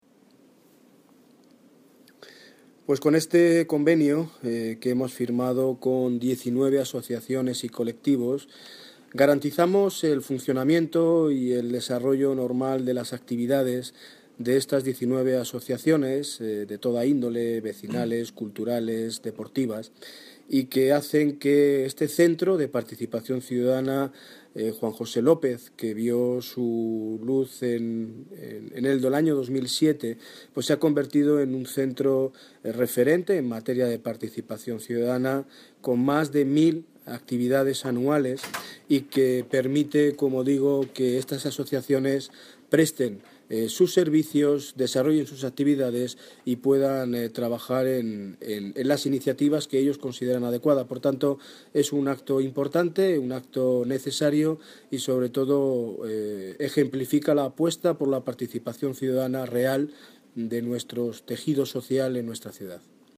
Audio - Daniel Ortiz (Alcalde de Mótoles) Sobre Cesión Espacios